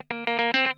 PICKIN 6.wav